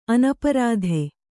♪ anaparādhe